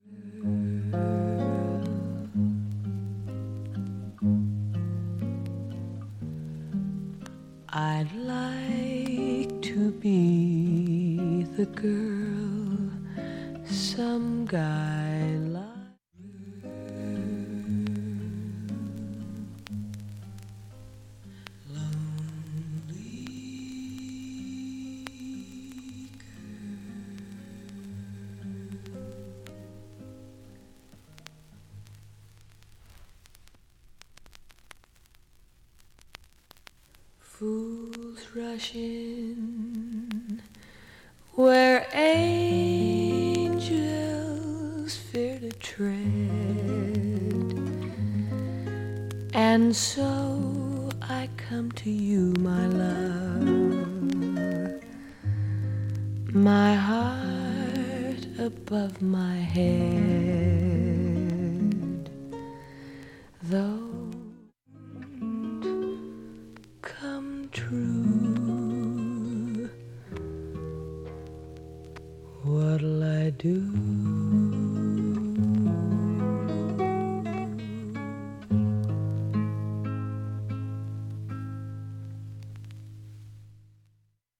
チリも無くクリアすぎて聴こえるかすかなものです。
盤面きれいです音質良好全曲試聴済み。
◆ＵＳＡ盤オリジナル Mono
しっとりとバラードを歌い上げる1956年の名作